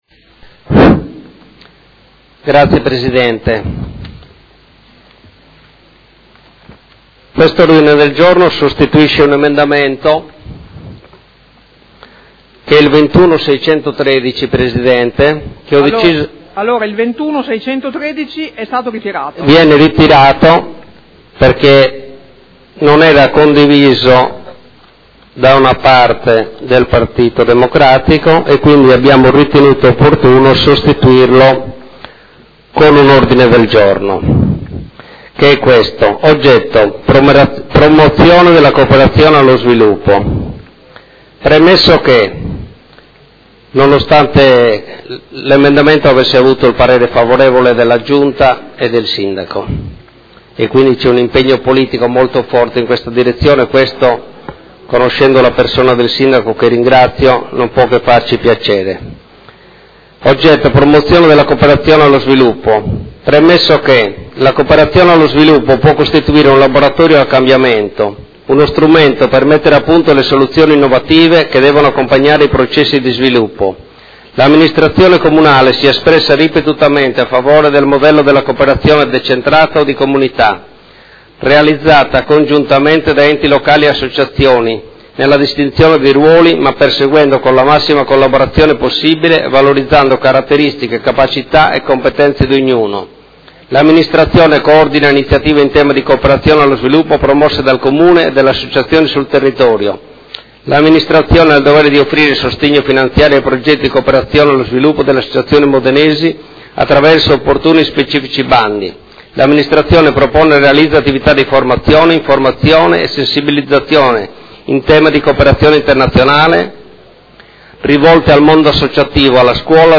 Seduta del 25 febbraio. Approvazione Bilancio: presentazione odg prot. 28043